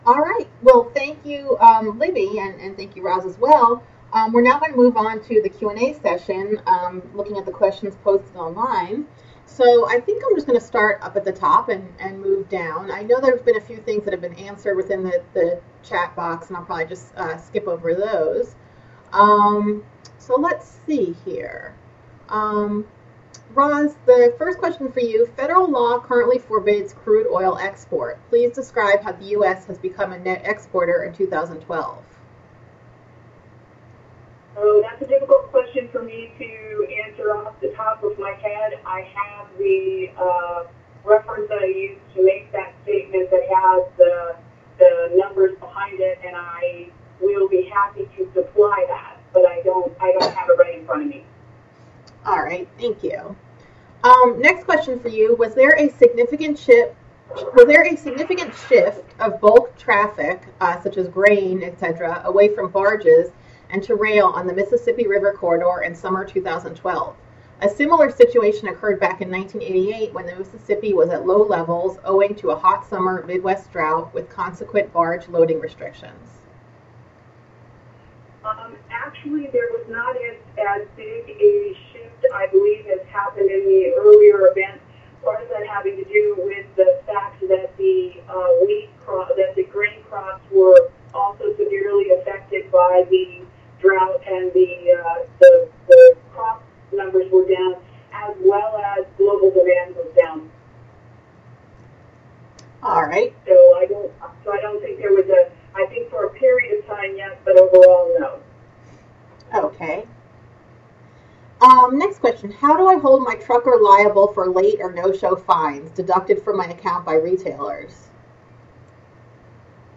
August 2013 - Talking Freight - Freight Planning - Planning - FHWA